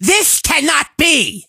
carl_hurt_vo_03.ogg